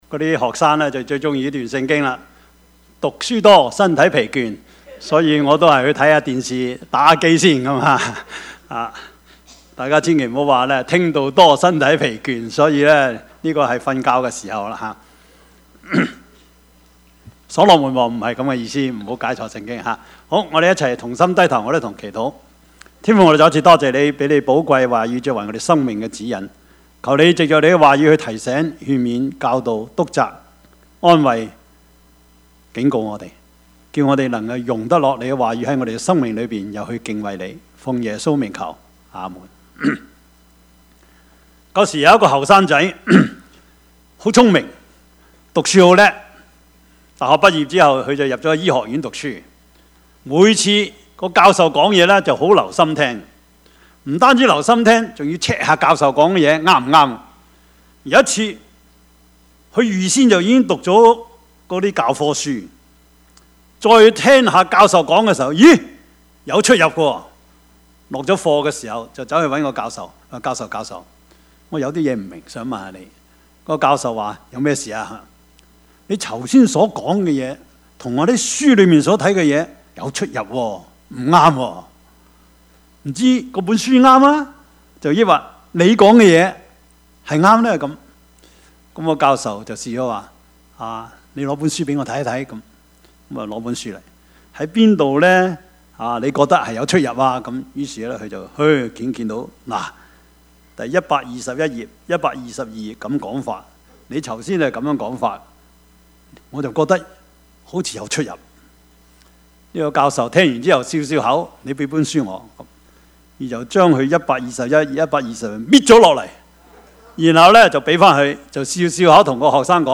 Service Type: 主日崇拜
Topics: 主日證道 « 虛有其表的事奉敬拜生活 馬利亞 – 愛是不保留 »